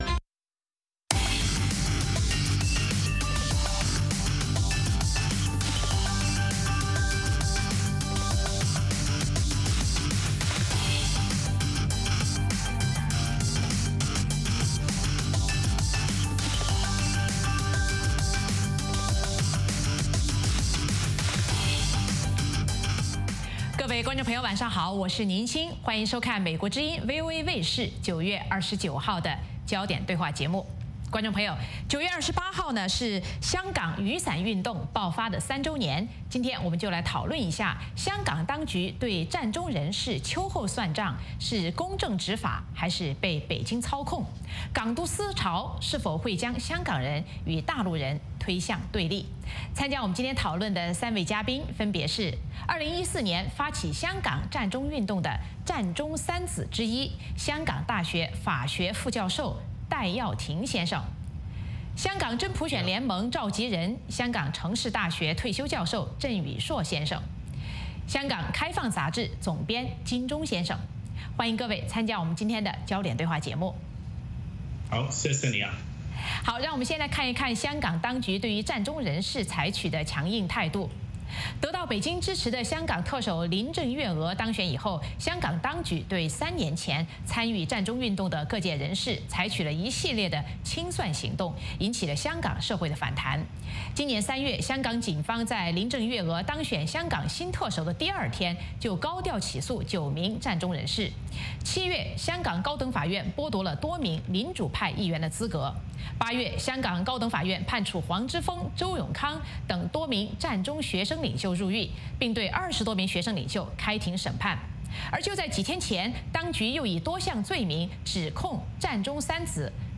美国之音中文广播于北京时间早上6－7点重播“焦点对话”节目。《焦点对话》节目追踪国际大事、聚焦时事热点。邀请多位嘉宾对新闻事件进行分析、解读和评论。